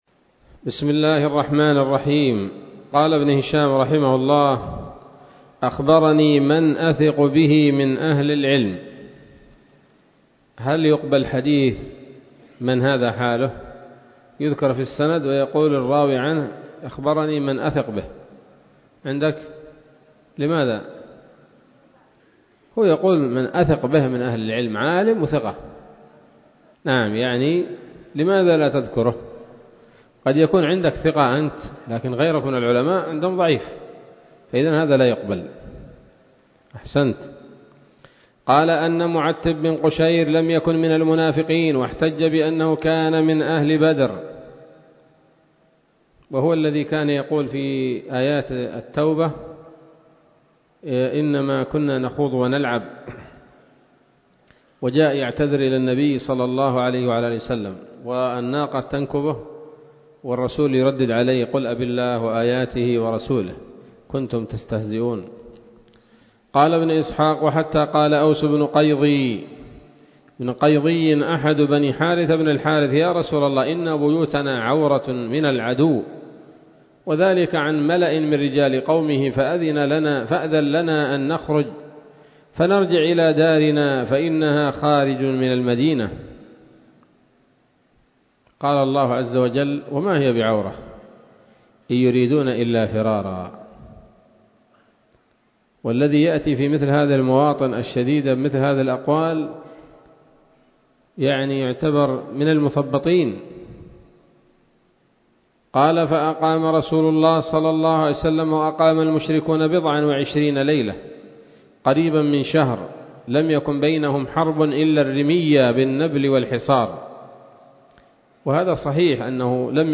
الدرس الأول بعد المئتين من التعليق على كتاب السيرة النبوية لابن هشام